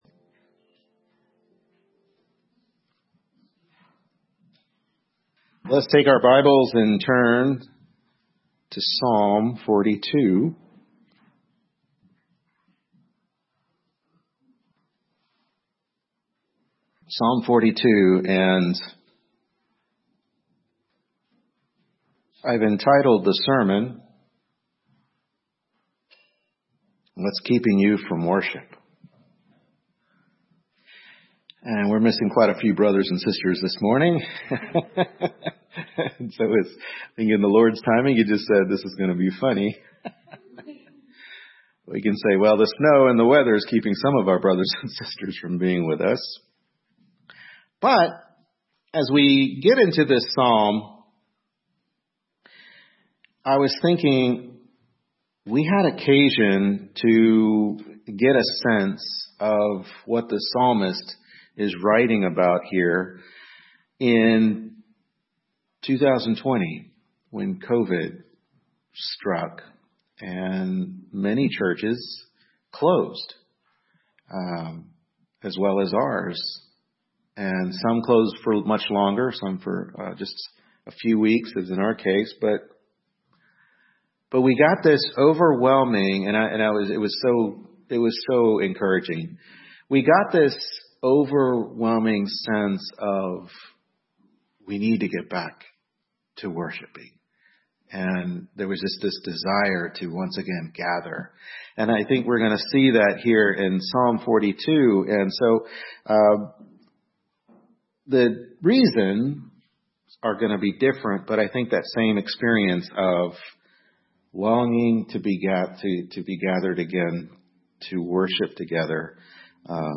Psalm 42 Service Type: Morning Worship Service Psalm 42 What’s Keeping You From Worship?